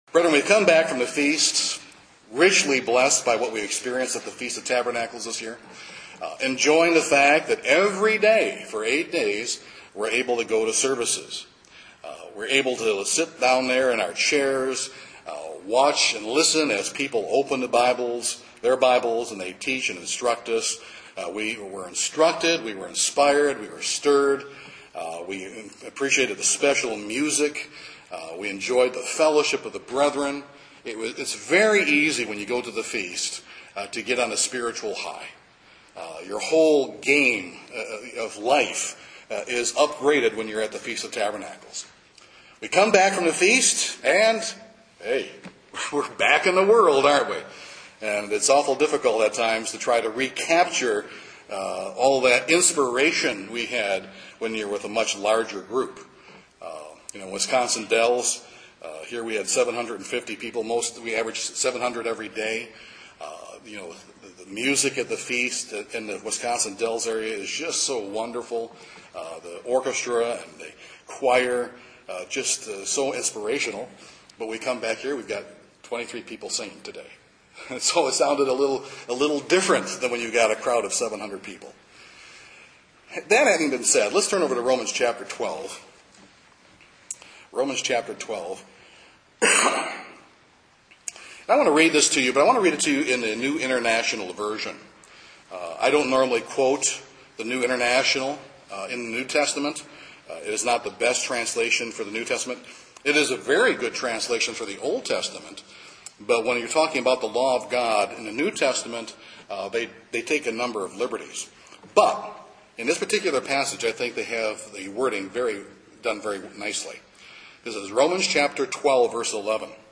But when we come home, it is all too easy to become distracted by life’s activities. This sermon answers the question of how we have a more consistent walk with God.